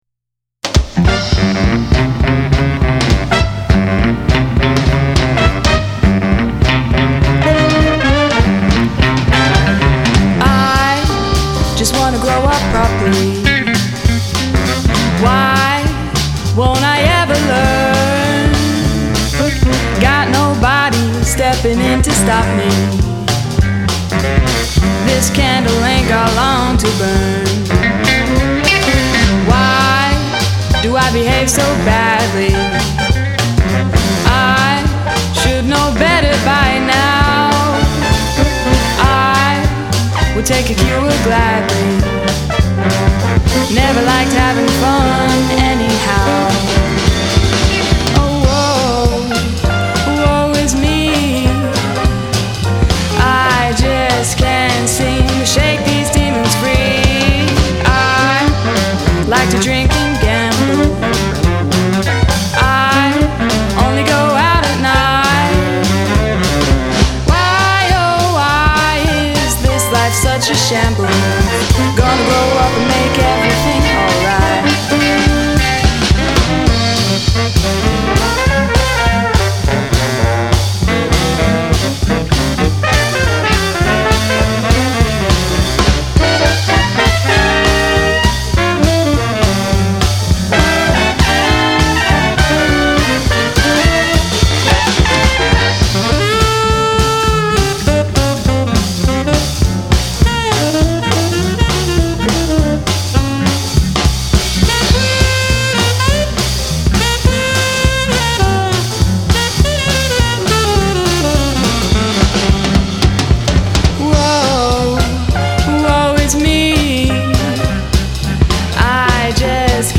Jazz/Swing/RnB band